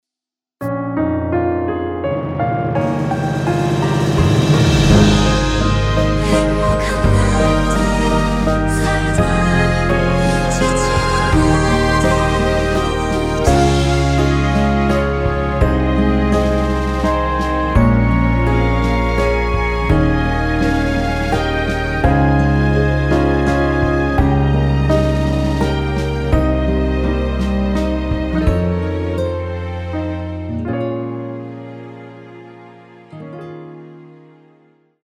이곡의 코러스는 미리듣기에 나오는 부분이 전부 입니다.다른 부분에는 코러스가 없습니다.(미리듣기 확인)
원키 코러스 포함된 MR입니다.
Ab
앞부분30초, 뒷부분30초씩 편집해서 올려 드리고 있습니다.